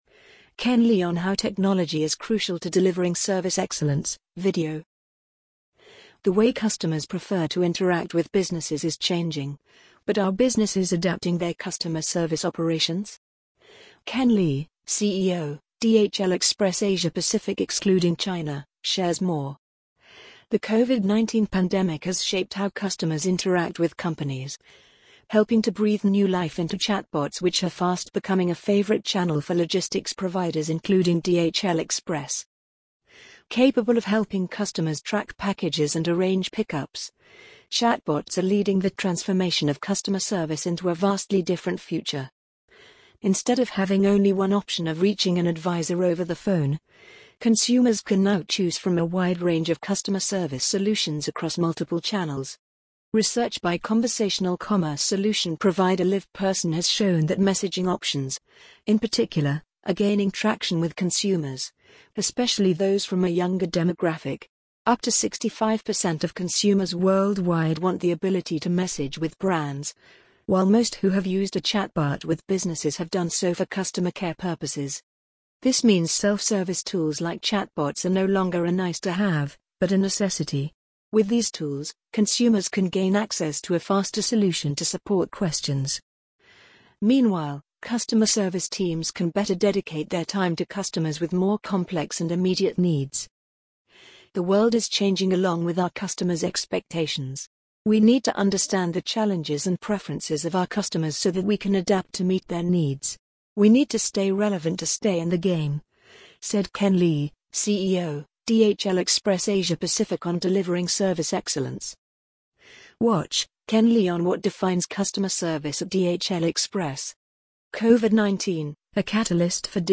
amazon_polly_15234.mp3